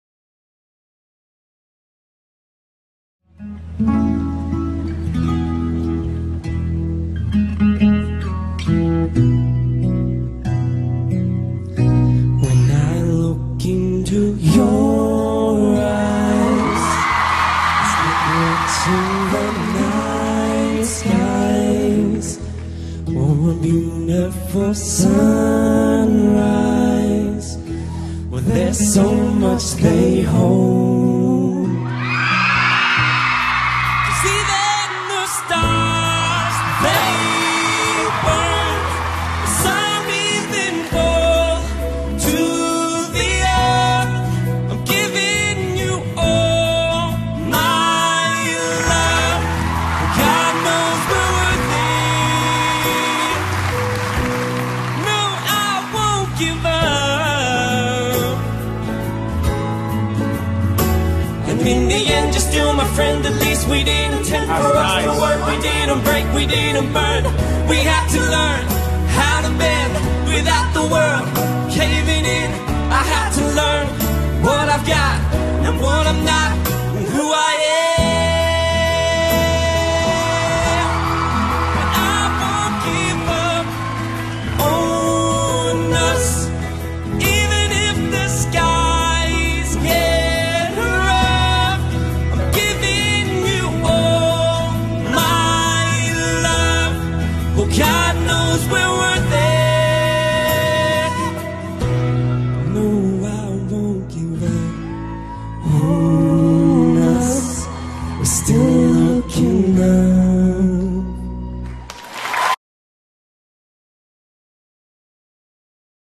Dual Vocals, Guitar, Bass, Drums/Percussion